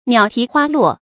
鸟啼花落 niǎo tí huā luò
鸟啼花落发音